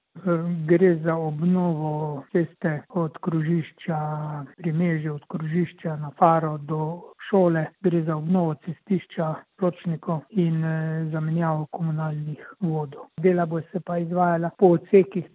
Na Prevaljah so danes na delu ceste Spodnji kraj – Na Fari – Polje pričeli s sanacijo poškodovanega cestišča. Župan Občine Prevalje Matic Tasič: